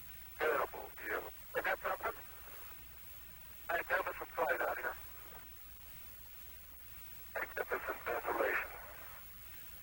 Những lời đầu tiên của Aldrin sau khi ông đặt chân lên Mặt Trăng